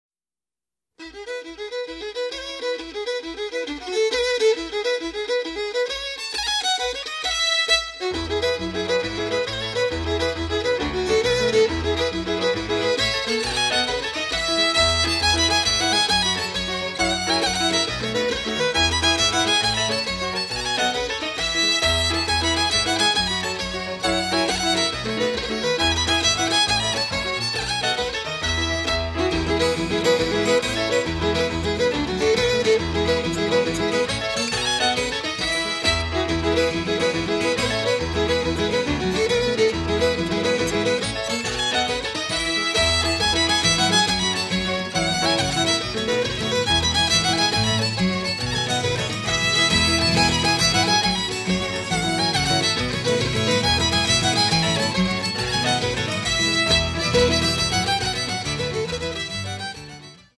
2. Jigs